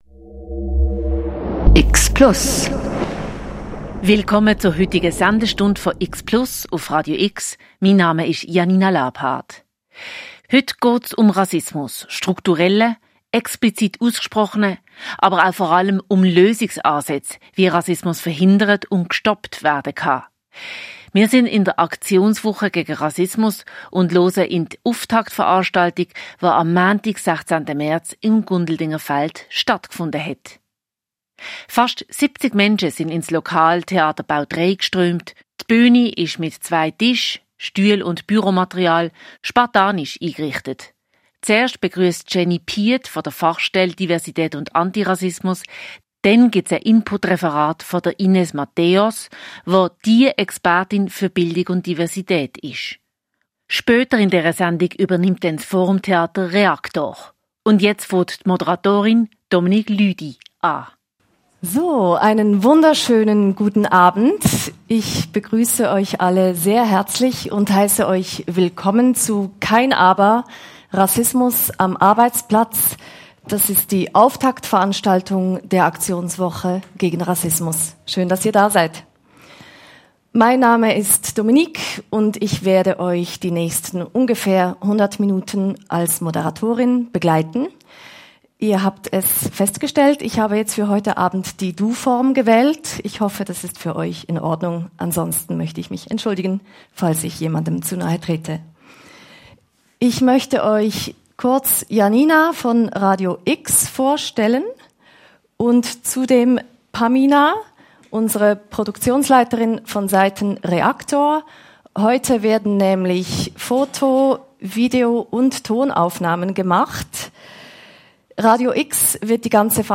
Beschreibung vor 2 Wochen Als Auftakt der Aktionswoche gegen Rassismus fand am Montagabend, 16. März 2026, eine interaktive Veranstaltung im Gundeldingerfeld in Basel statt.
Das Publikum wurde explizit einbezogen und nach Lösungen und Vermeidung von rassistischen Vorfällen gefragt.